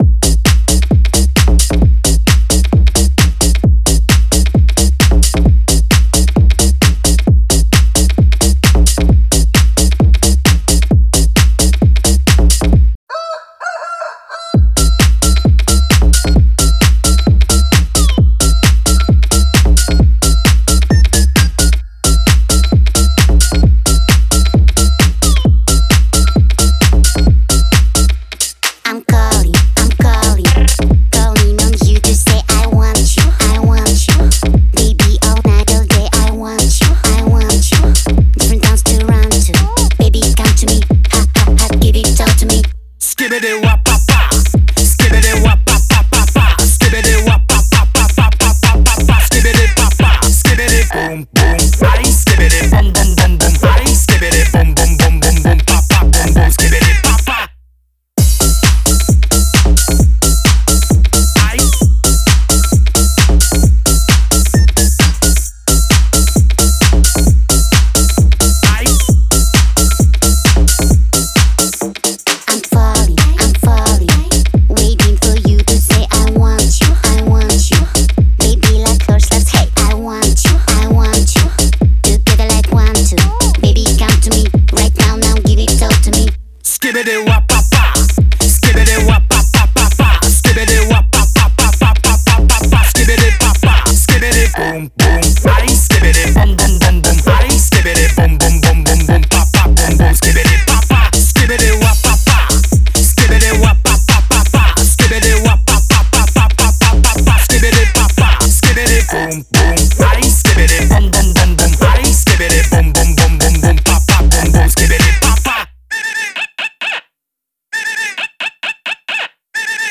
энергичная и зажигательная композиция
которая сочетает в себе элементы электронной музыки и хаус.